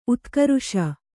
♪ utkaruṣa